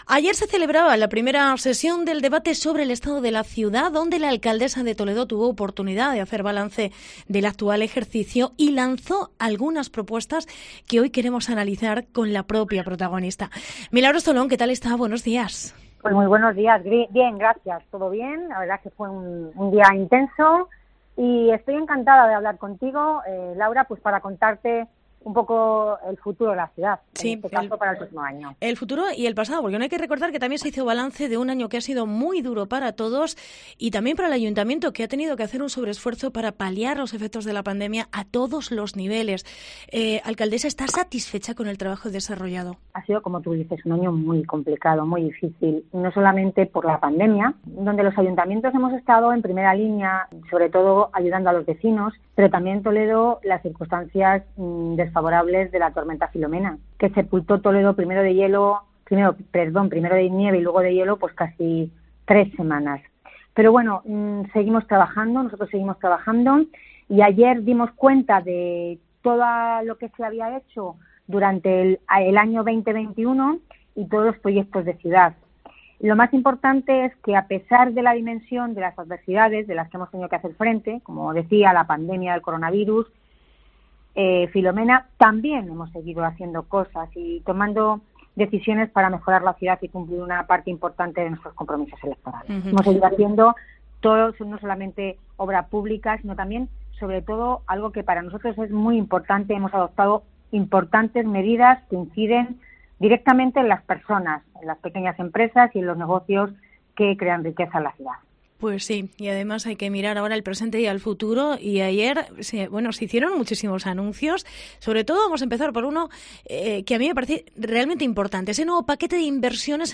AUDIO: Entrevista Milagros Tolón